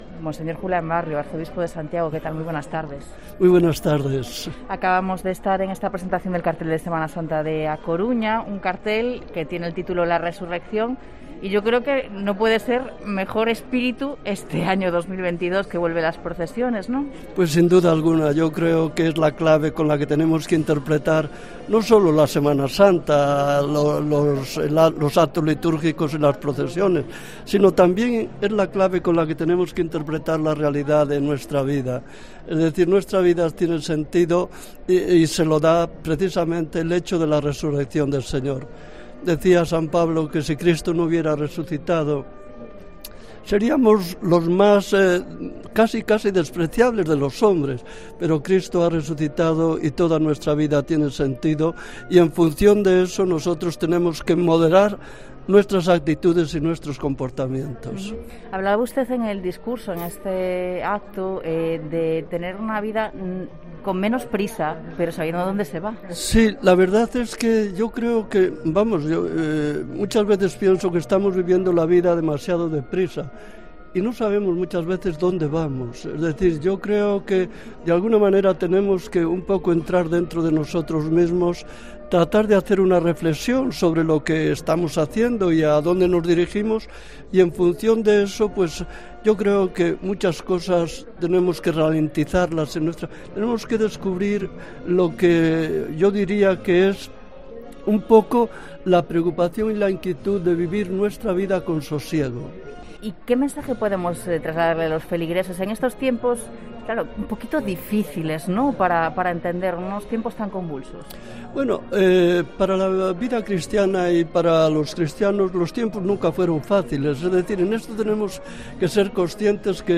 Entrevista a Monseñor Julián Barrio, arzobispo de Santiago, en la presentación del cartel de la Semana Santa